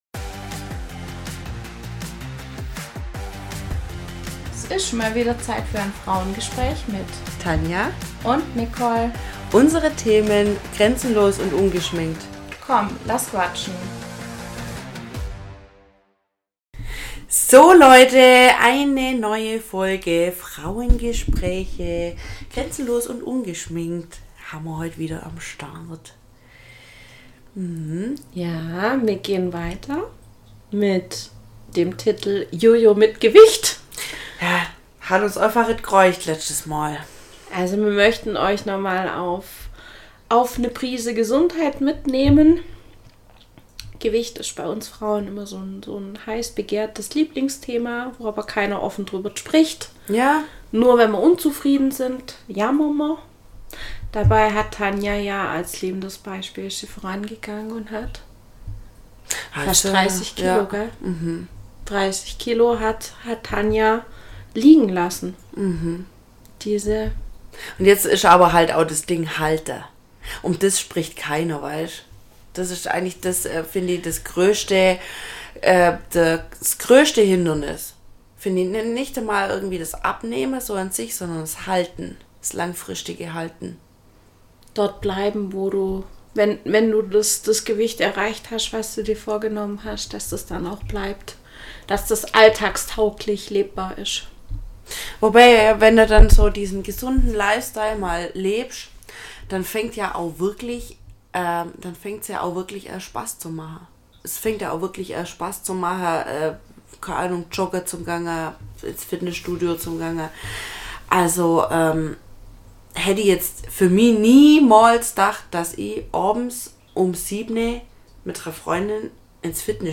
#009 Jo-jo mit Gewicht - weiter gehts mit Gesundheit ~ Frauengespräche │ grenzenlos & ungeschminkt Podcast